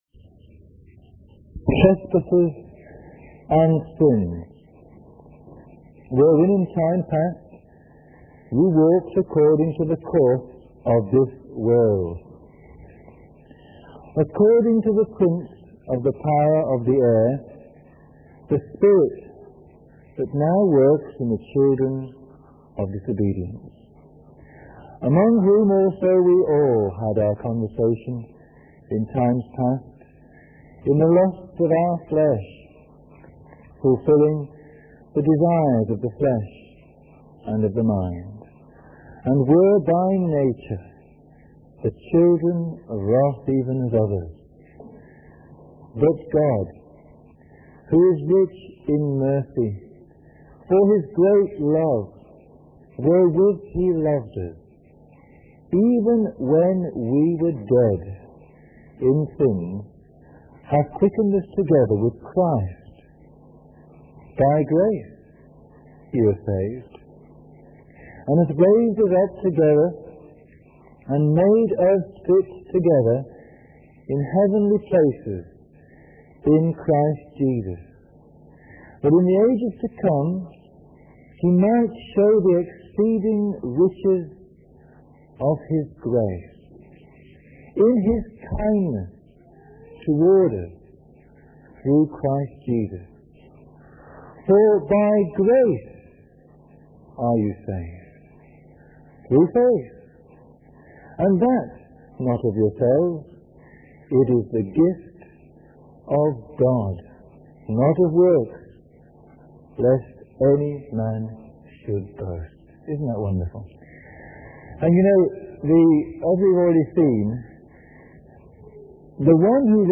In this sermon, the preacher emphasizes the concept of grace and its significance in the lives of believers.